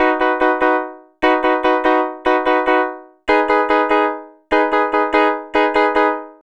Twisting 2Nite 2 Piano-B.wav